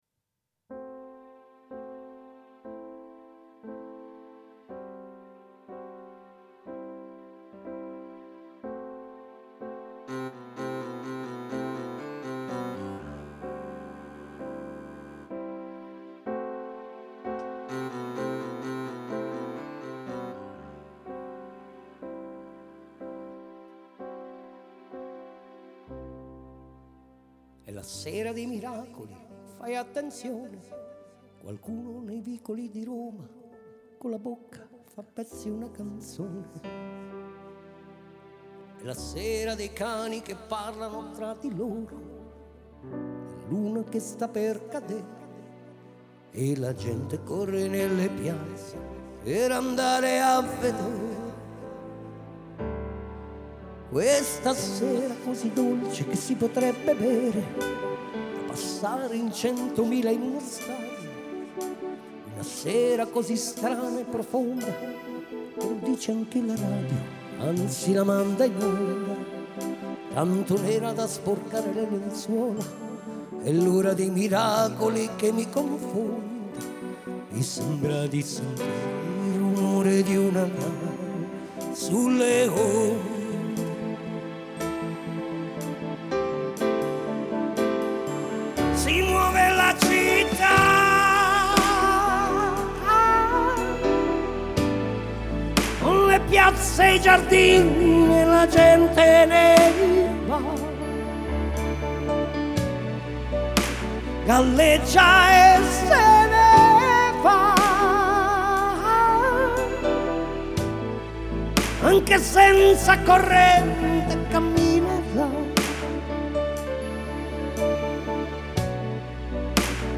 Vocal Virtuoso